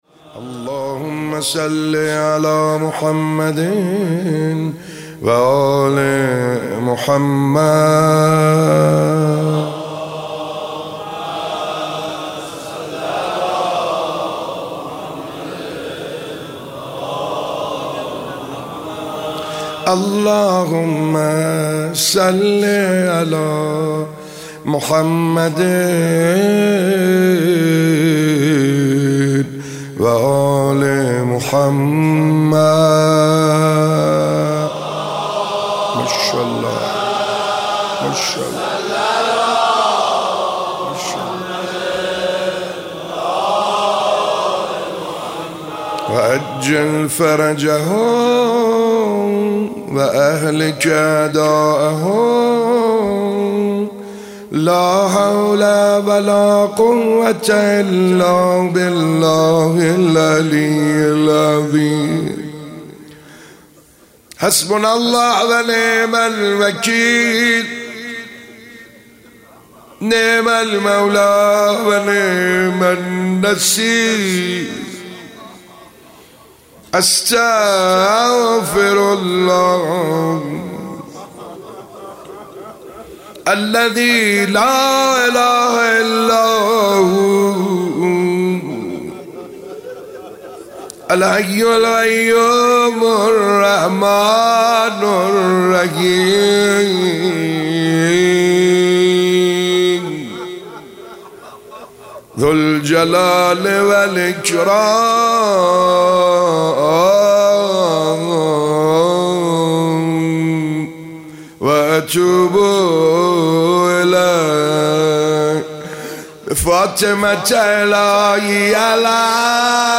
هفتگی 26 بهمن 96 - دعاخوانی